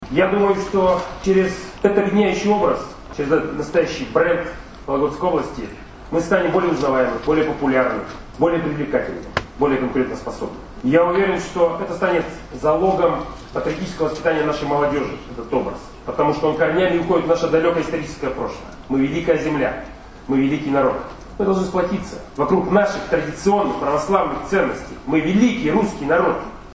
Олег Кувшинников рассказывает о едином бренде Вологодчины